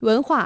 文化 wénhuà 文化